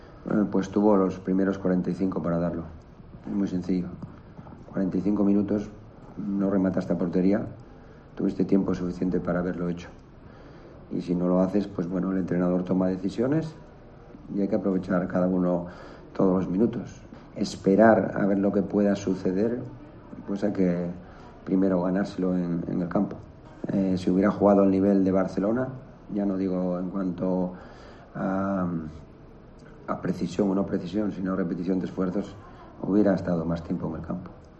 Marcelino cambió a su delantero al descanso pese a ir 0-0. Luego en rueda de prensa le dio un palo: "Tuvo tiempo para rematar a portería y no lo hizo. Hay que ganárselo".